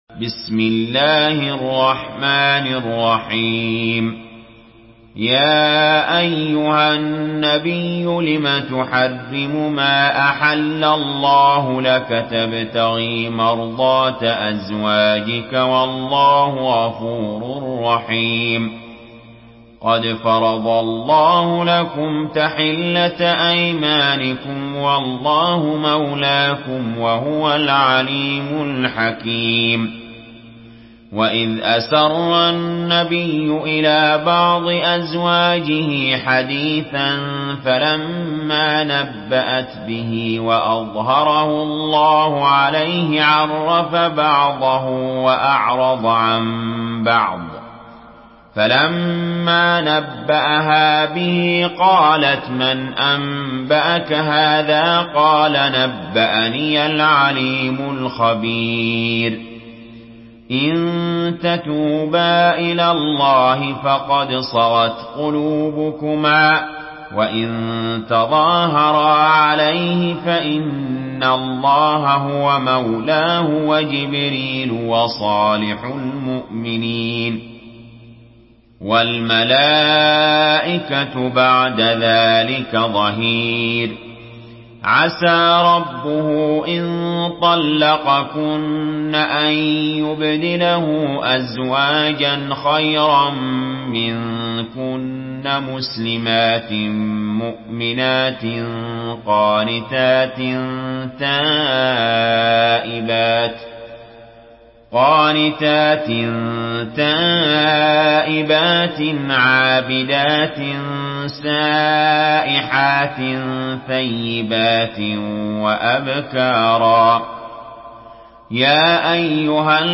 Surah التحريم MP3 in the Voice of علي جابر in حفص Narration
Surah التحريم MP3 by علي جابر in حفص عن عاصم narration.
مرتل حفص عن عاصم